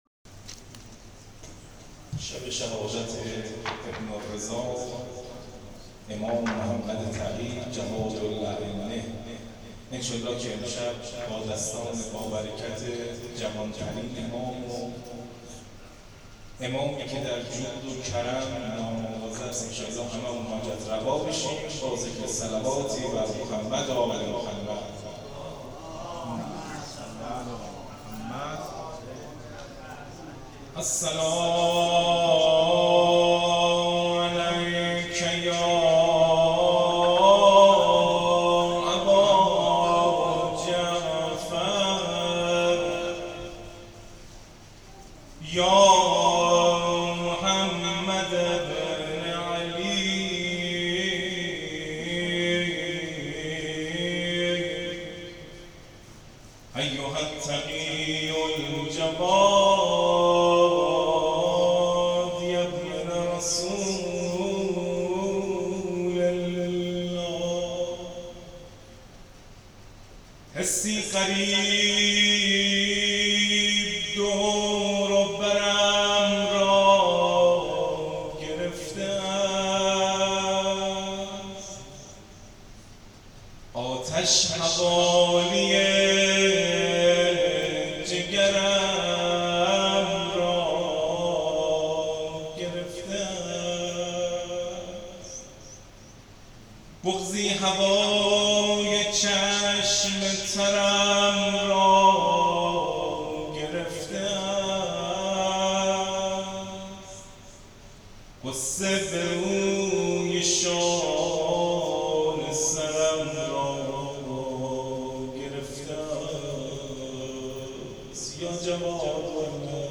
مراسم شهادت امام جواد علیه السلام